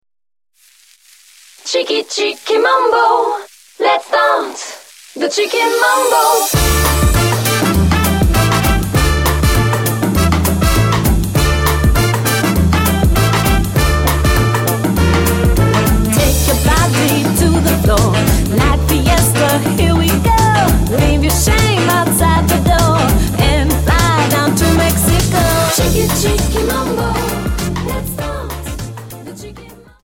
Dance: Samba